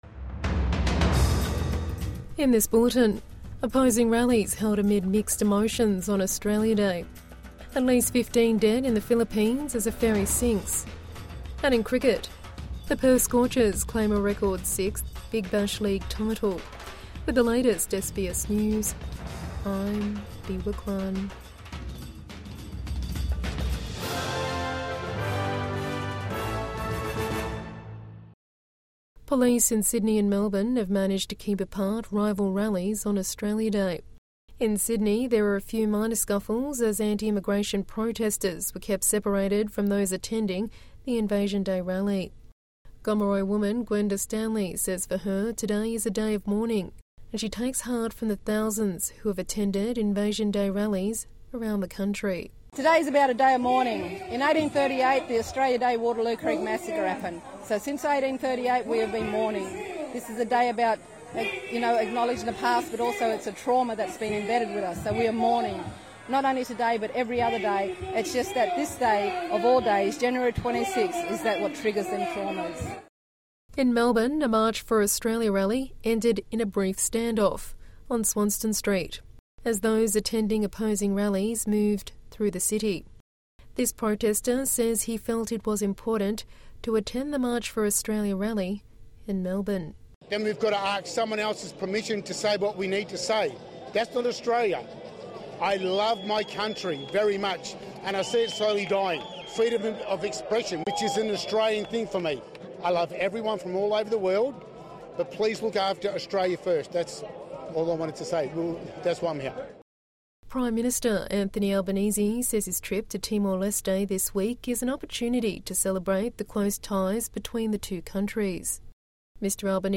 Opposing rallies held amid mixed emotions on Australia Day | Evening News Bulletin 26 January 2026